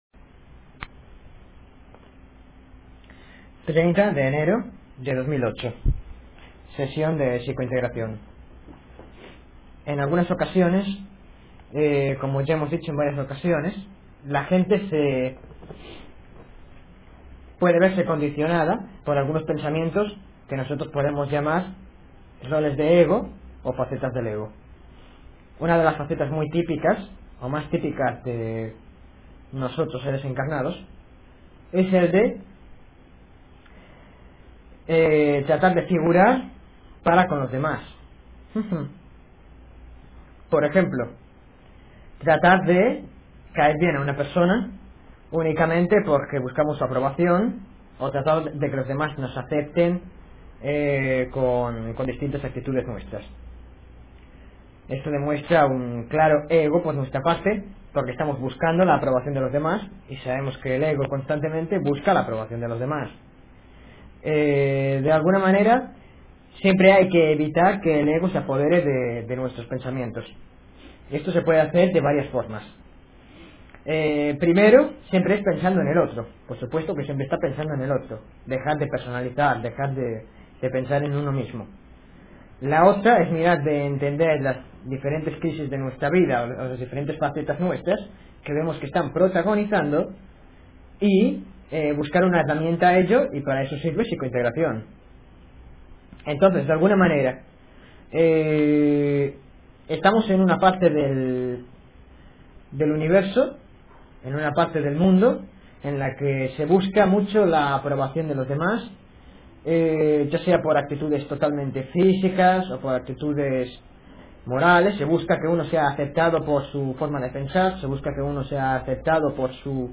Sesión de Psicointegración.